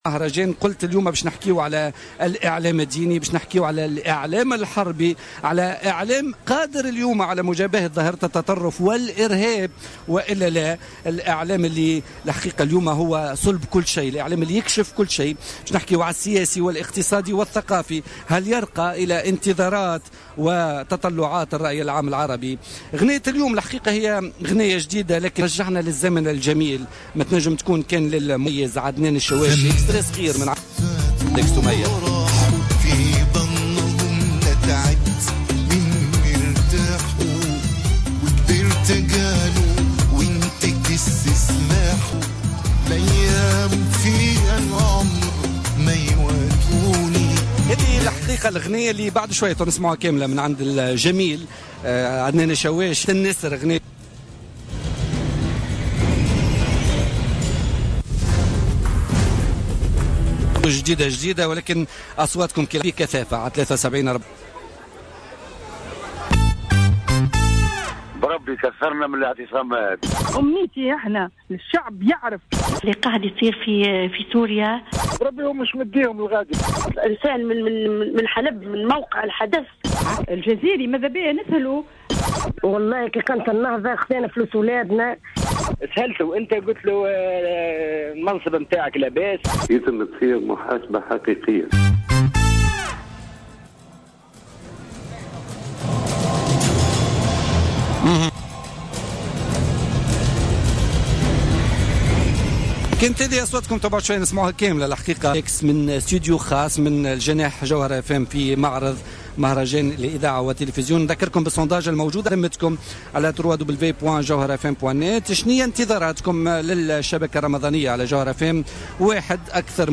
أكد عبد الرزاق الطبيب رئيس مدير عام التلفزة التونسية ضيف بوليتيكا اليوم الثلاثاء 3 ماي 2016 الإعلام العمومي انتقل بعد الثورة من اعلام حكومي لإعلام عمومي بشهادة الملاحظين والمراقبين من المجتمع المدني المحلي والدولي.